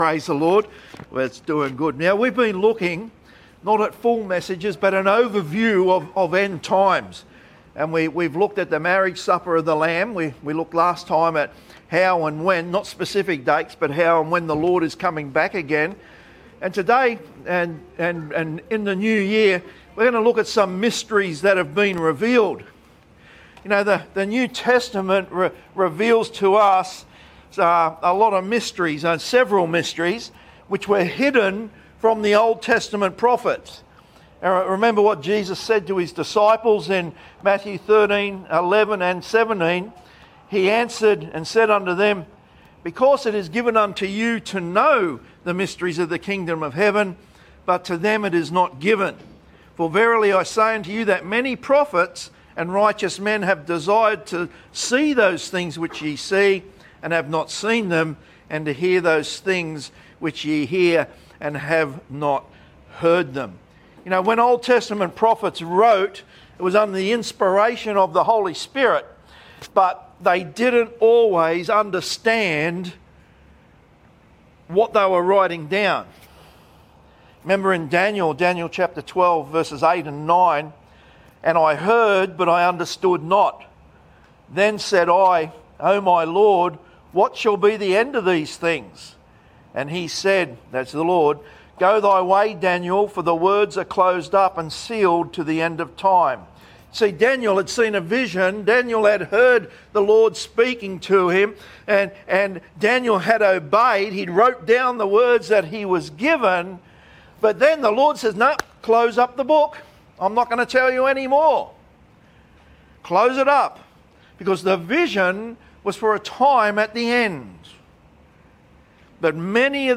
our PM sermon on 22 December 2024 at the Gold Coast.